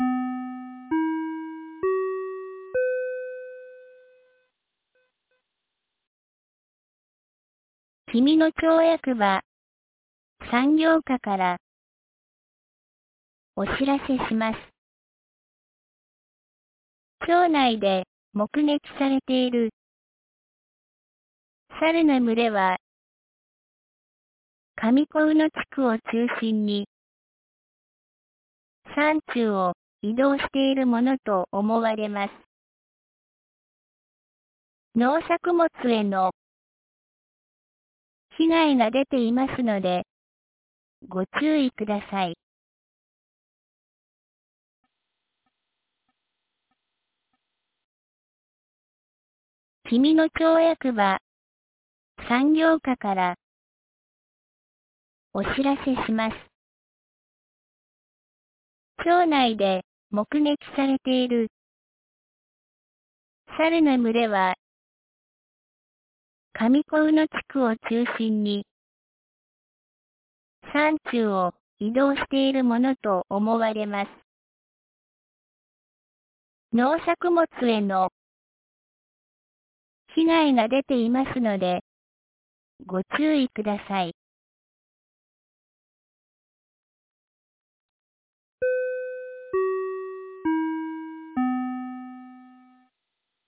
2025年08月29日 12時36分に、紀美野町より上神野地区へ放送がありました。